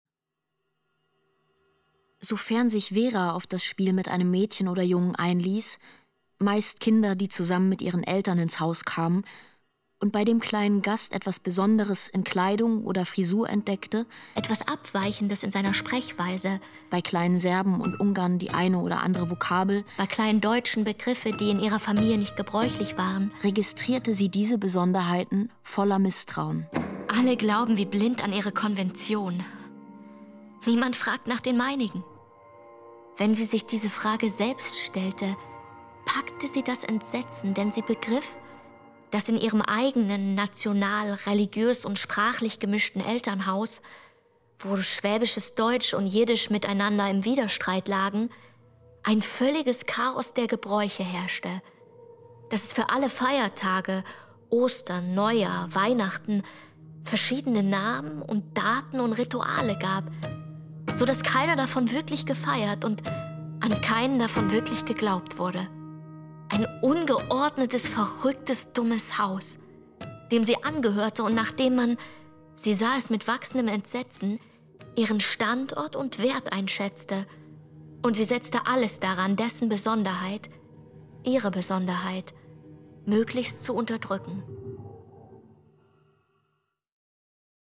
Bericht, Erzählung und Dialog wechseln einander ab, wodurch eine gewisse Distanz entsteht.